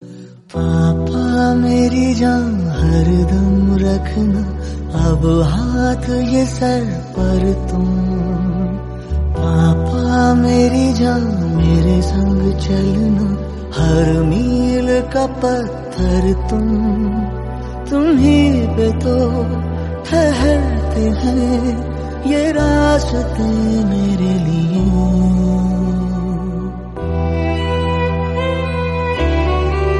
an emotional song, with gentle and deep melodies